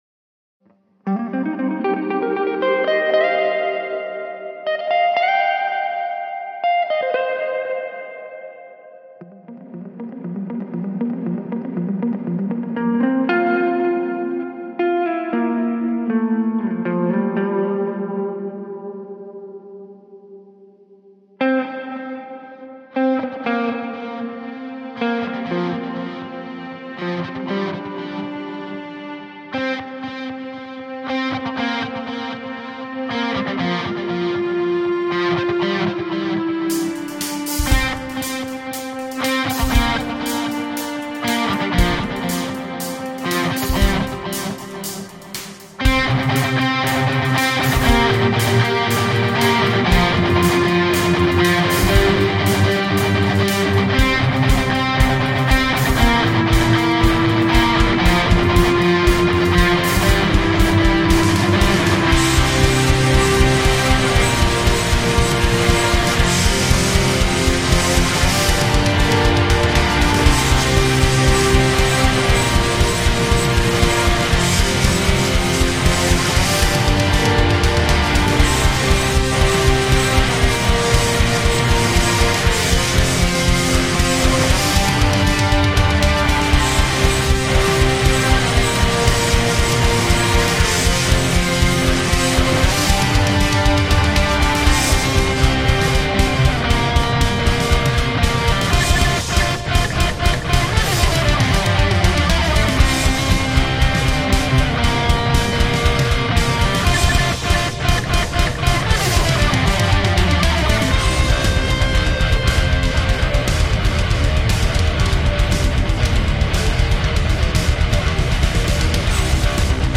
with lots of guitars